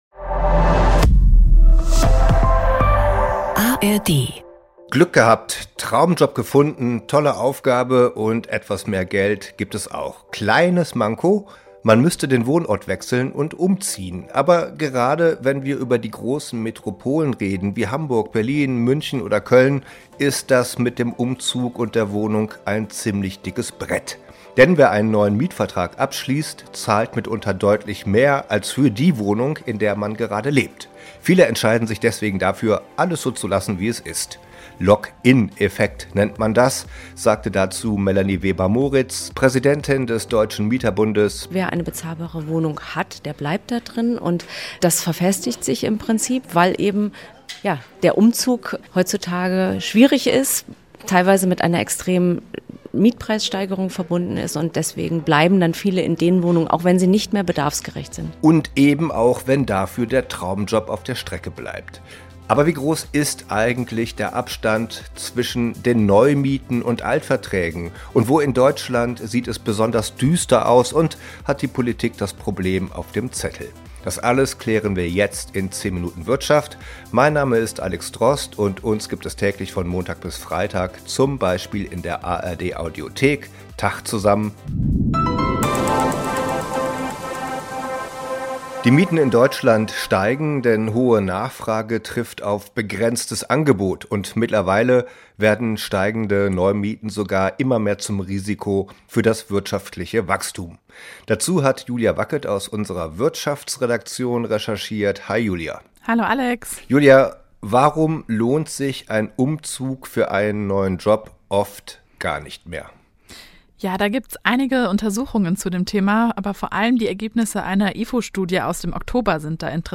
die hohen Mieten als Bremse für einen mobilen Arbeitsmarkt sprechen